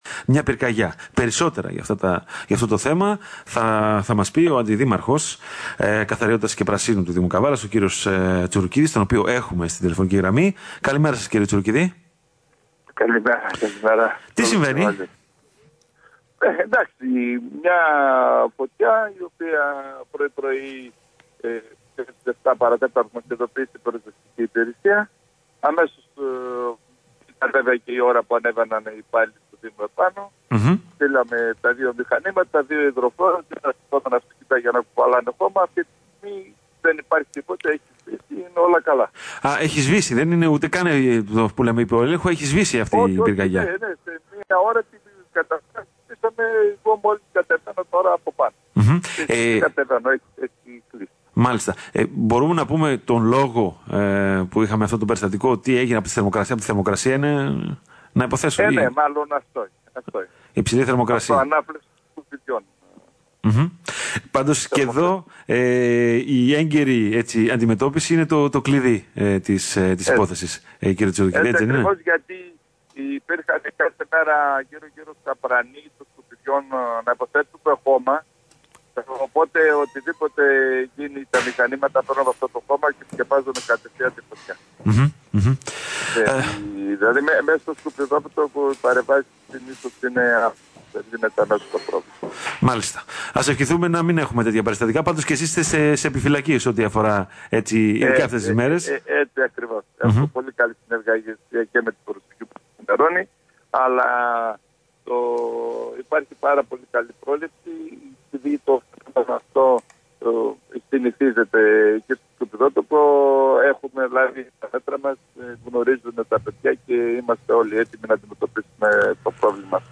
Δηλώσεις του Αντιδημάρχου Ανέστη Τσουρουκίδη (ηχητικό)